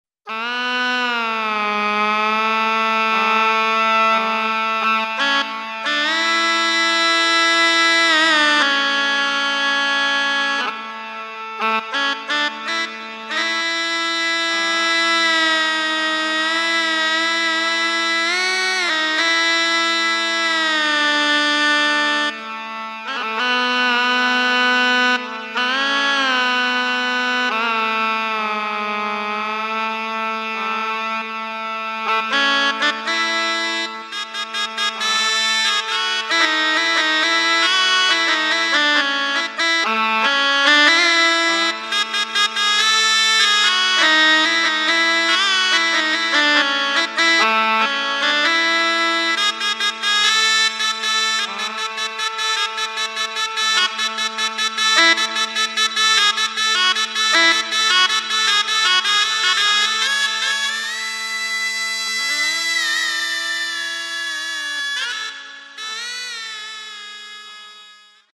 Shehnai
The shehnai is a quadruple reeded instrument played in grand and auspicious events.
shehnai.mp3